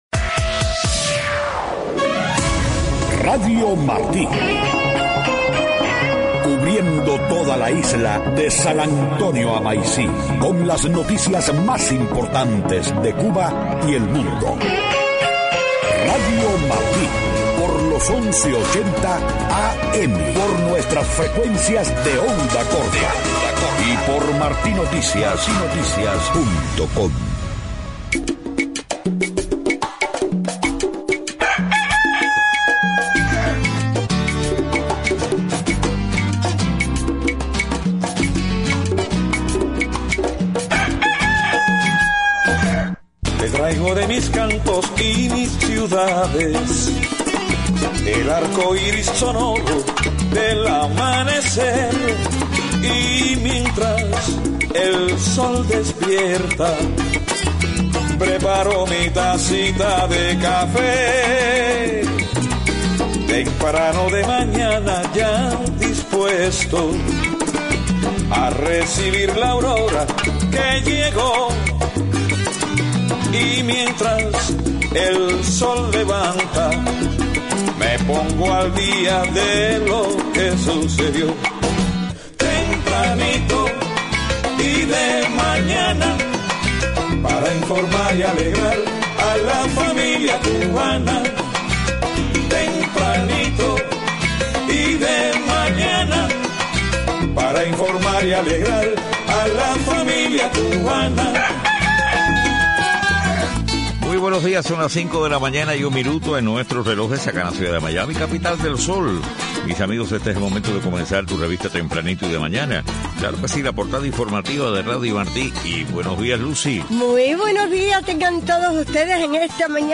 5:00 a.m. Noticias: Opositores en la isla escépticos sobre avances en derechos humanos en próximas negociaciones entre la UE y Cuba. Colombia pide a la Corte Penal Internacional que investigue y captura al presidente Maduro, de Venezuela. Ex presidente guatemalteco Otto Pérez Molina es llevado a un cuartel militar bajo custodia.
Lilian Tintori, esposa del opositor venezolano encarcelado, Leopoldo López, saluda a Radio Martí.